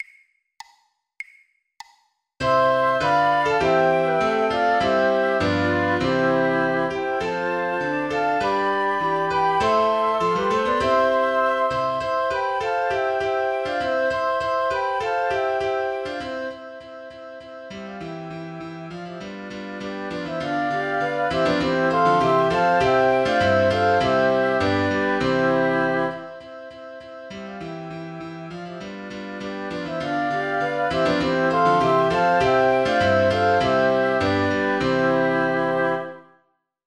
Playback Crowd 2 100 bpm
joy_to_the_world_Flöte-Klavier.mp3